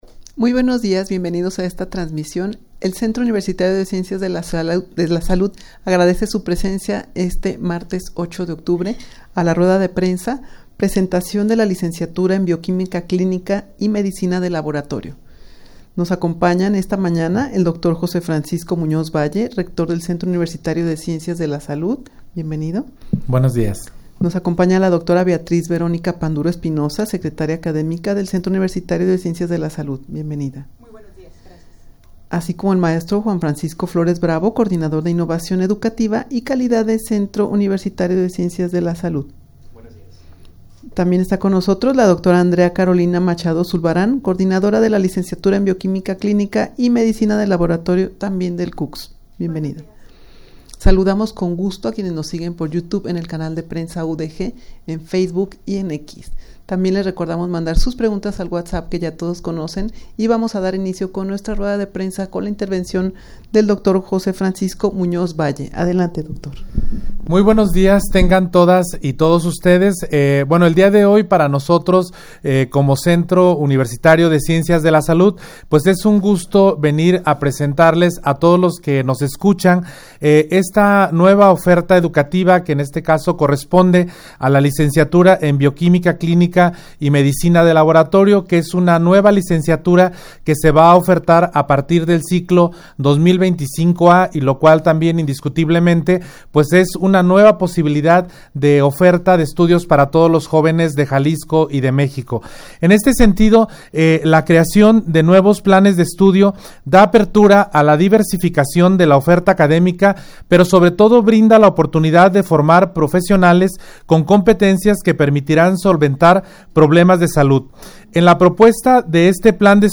Audio de la Rueda de Prensa
rueda-de-prensa-22presentacion-de-la-licenciatura-en-bioquimica-clinica-y-medicina-de-laboratorio22.mp3